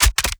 GUNMech_Rocket Launcher Reload_05_SFRMS_SCIWPNS.wav